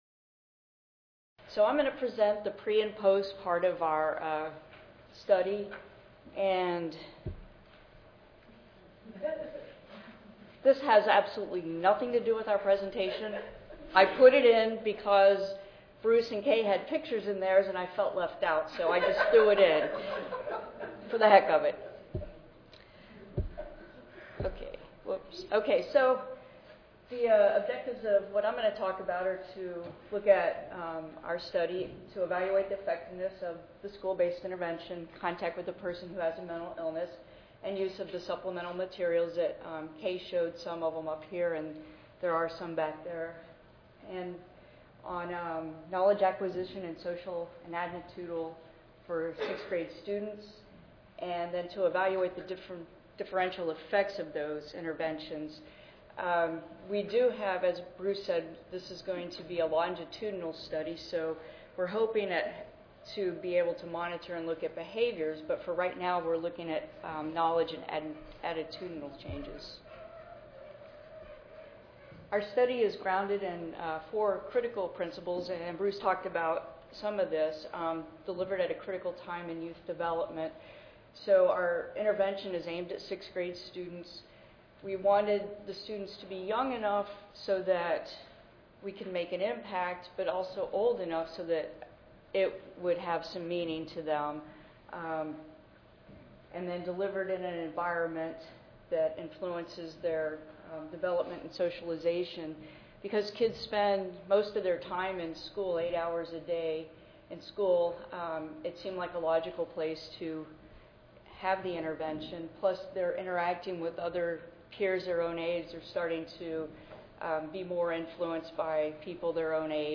141st APHA Annual Meeting and Exposition (November 2 - November 6, 2013): An Anti-Stigma Campaign: The Development and Evaluation of a School-Based Intervention